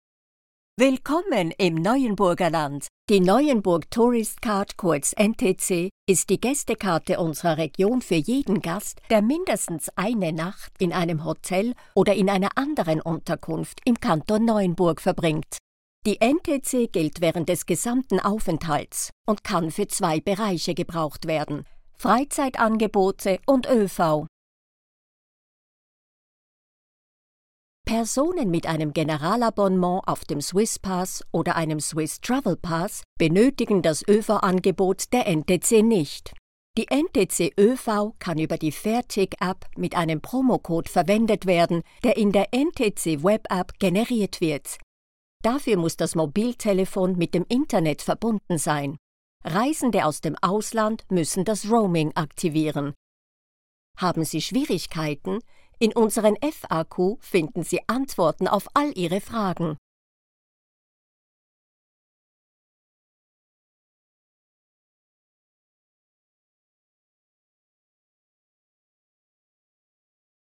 Deutsch (Schweiz)
Natürlich, Erwachsene, Freundlich, Vielseitig, Warm
E-learning
Swiss German voice actress recording in her home studio in Paris, France, in Swiss German, German and French and more rarely in English.